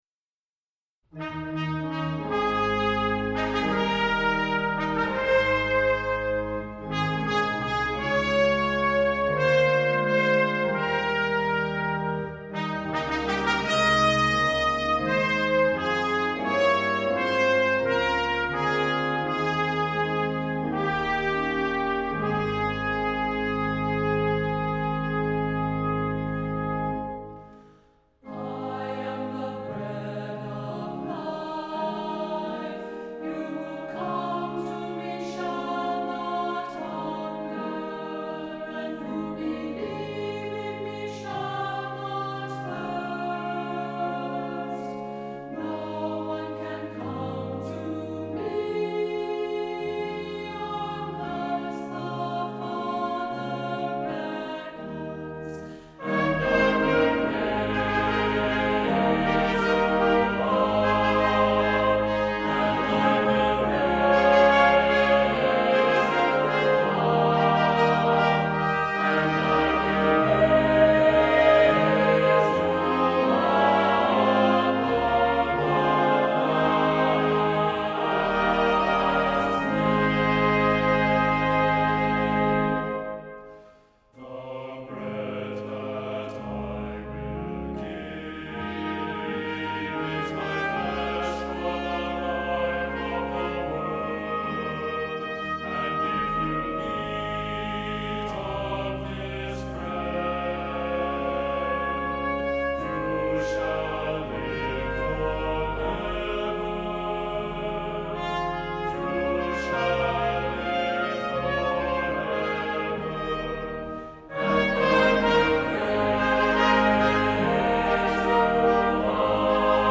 Voicing: SATB; Assembly